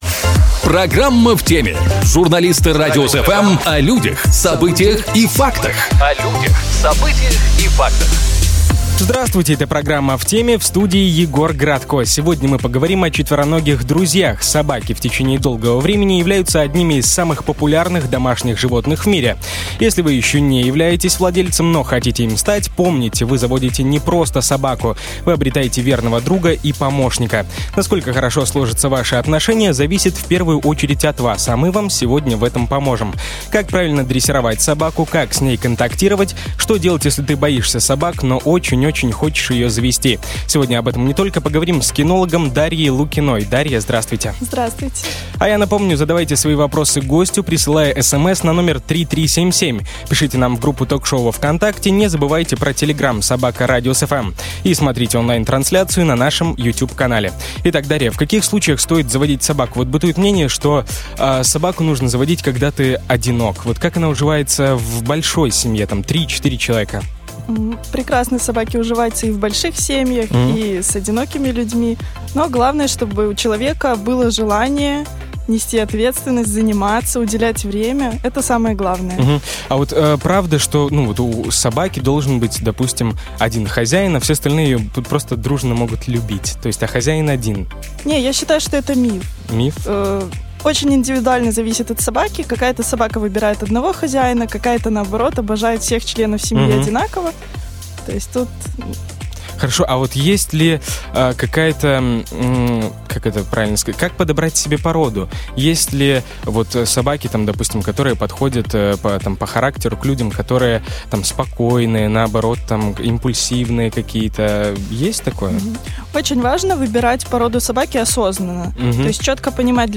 Сегодня "В теме!" мы поговорили о четвероногих друзьях.